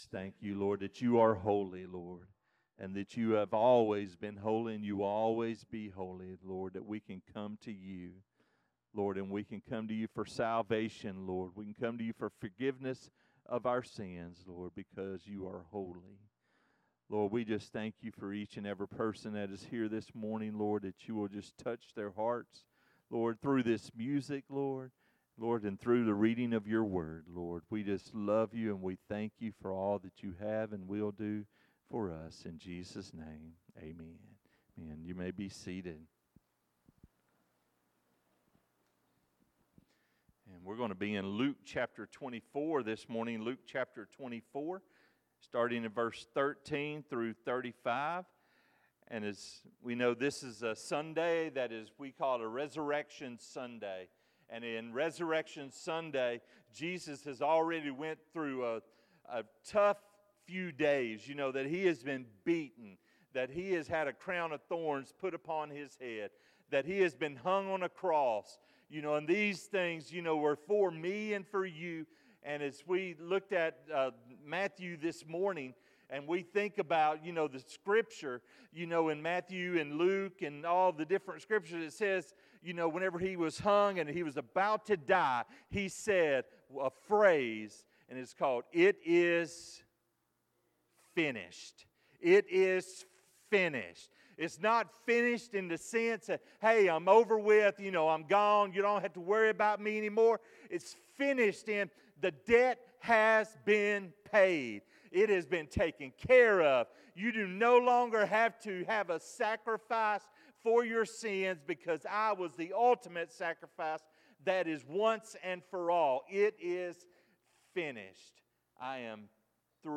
Sermons | First Southern Baptist Church Bearden
Sunday Morning 04-20-25 "Easter Sunday"